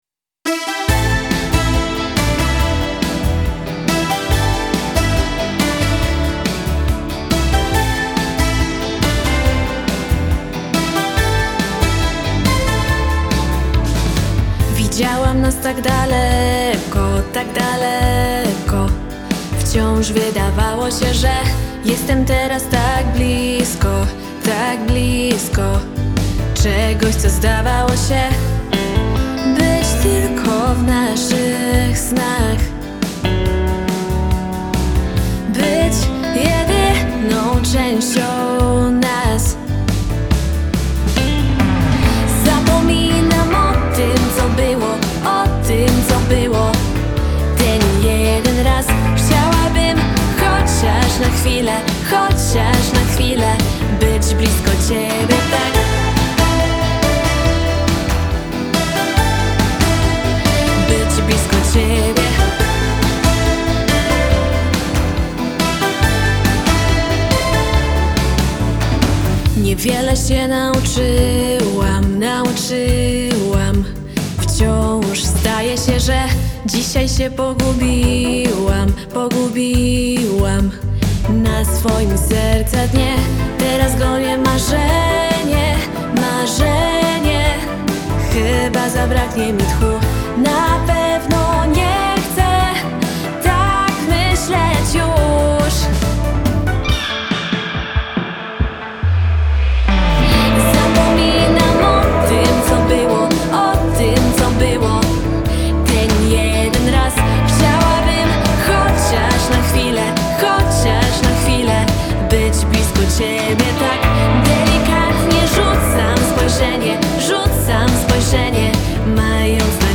ciekawy autorski pop